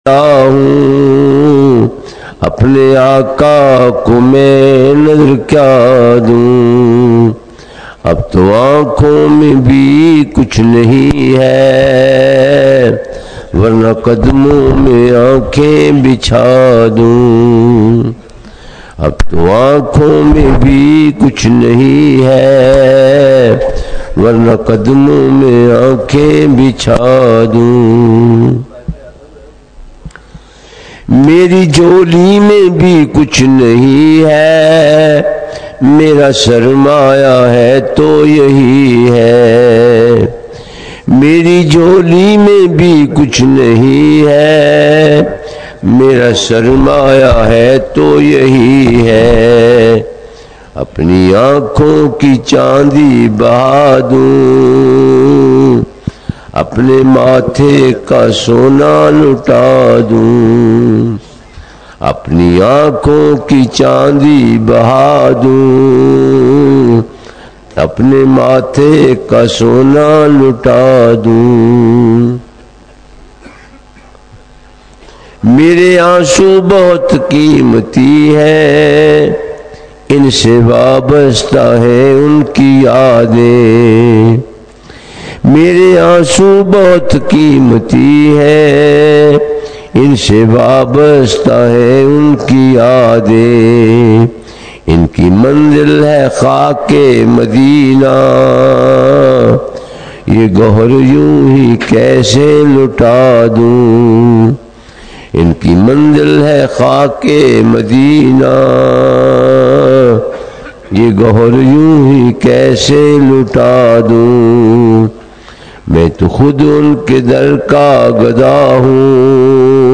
سوا ارب درود پاک کا چلہ ||چوتھی نشت||بعد نماز فجر|| 22 نومبر 2025